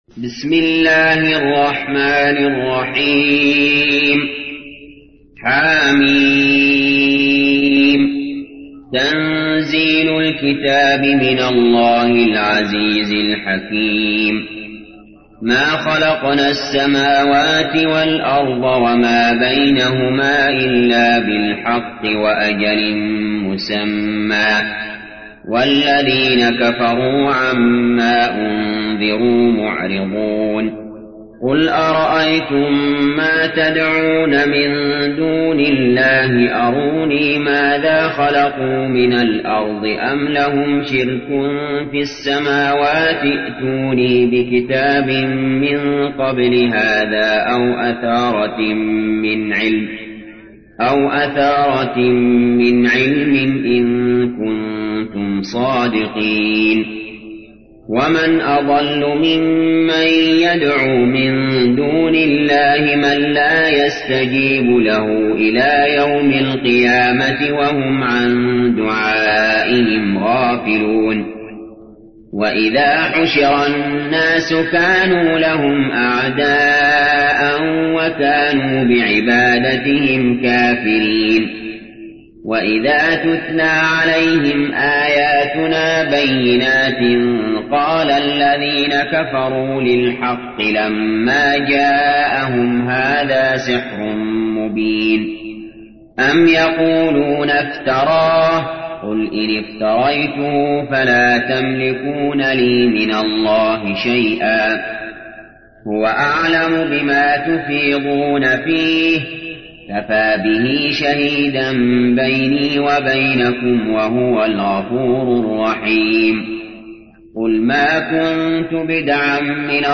تحميل : 46. سورة الأحقاف / القارئ علي جابر / القرآن الكريم / موقع يا حسين